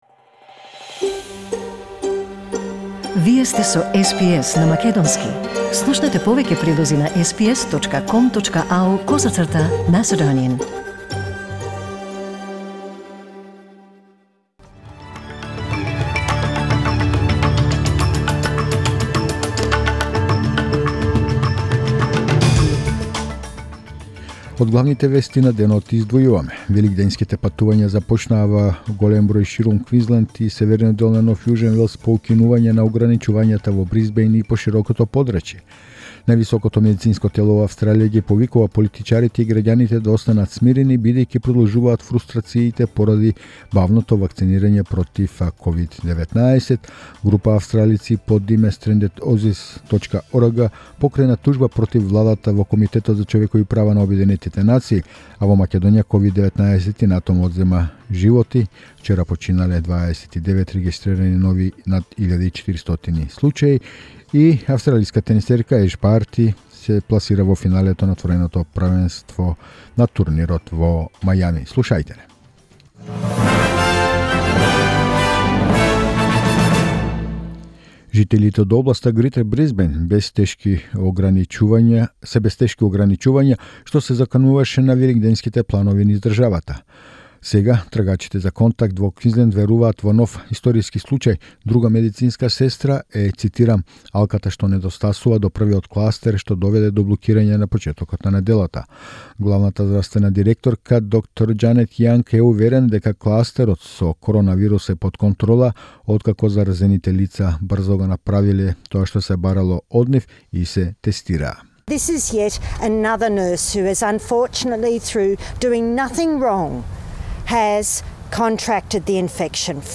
SBS News in Macedonian 2 April 2021